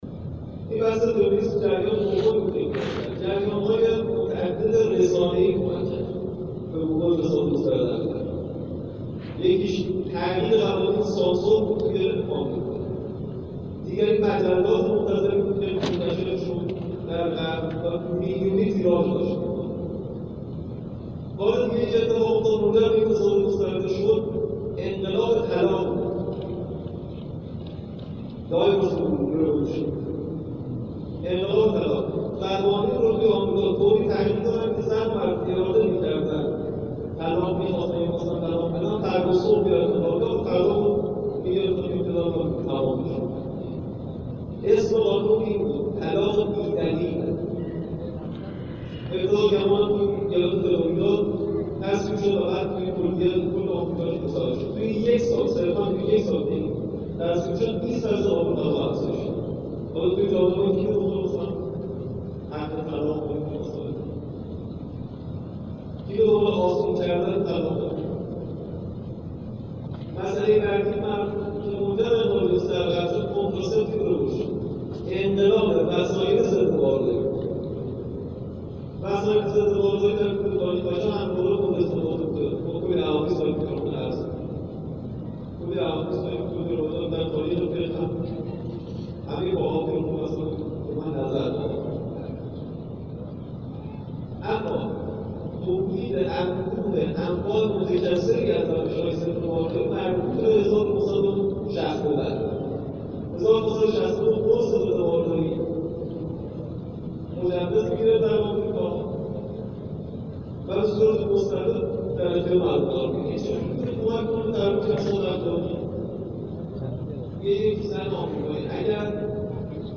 در همایش «بحران جمعیت؛ تنظیم خانواده و راهکارهای غلبه بر آن» که در مصلی قدس قم برگزار شد